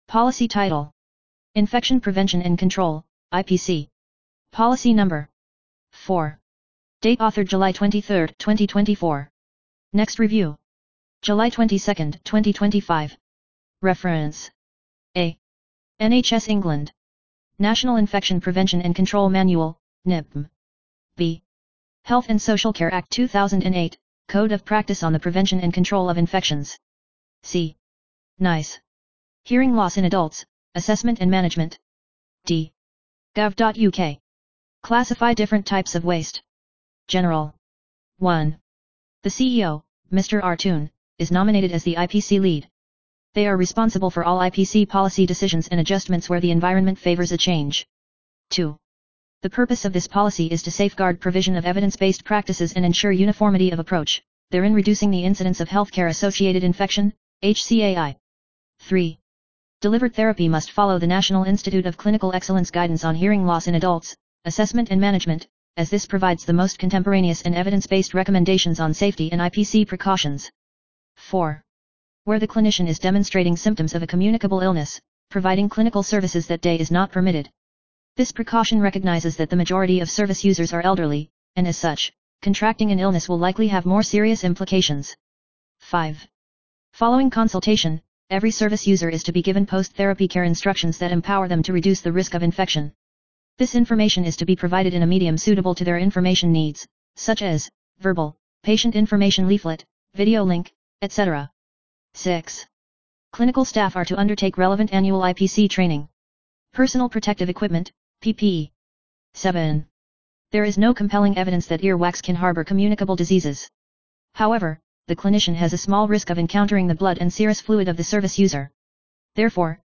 Narration of IPC Policy